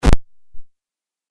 シンセ 8bit 踏み潰す ノイジー
ヒューイン